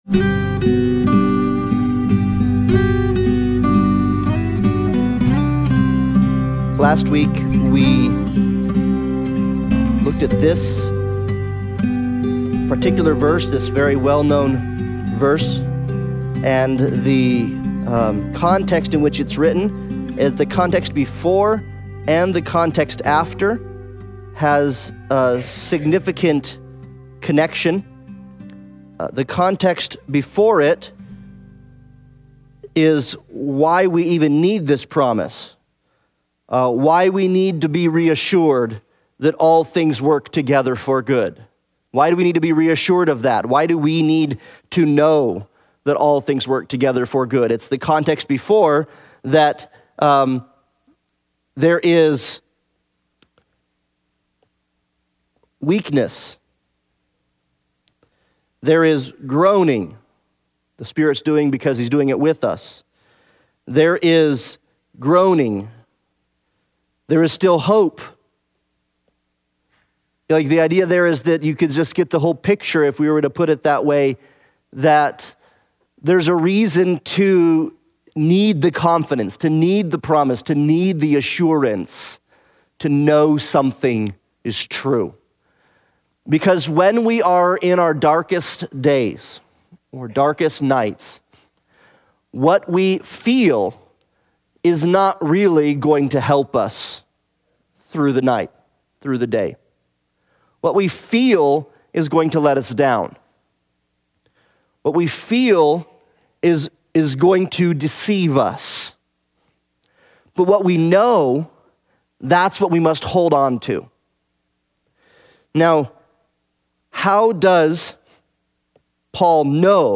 Romans Analysis Passage: Romans 8:27-30 Service Type: Sunday Bible Study « Watch Your Words